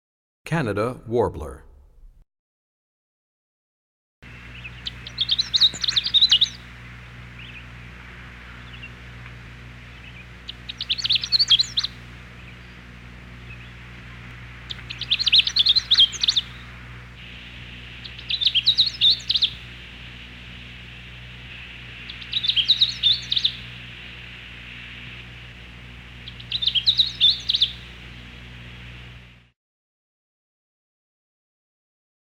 19 Canada Warbler.mp3